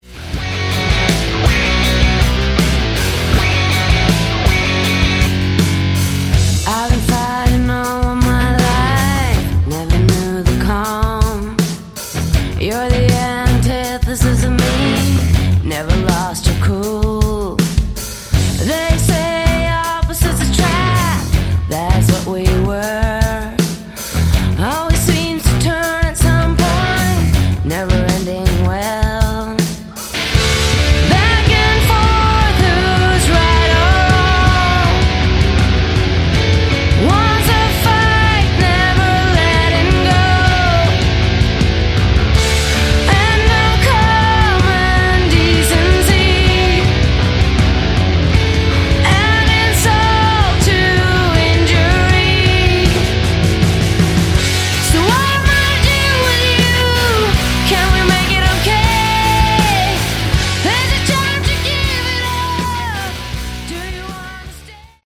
Category: Hard Rock
Good FF Modern Hard Rock!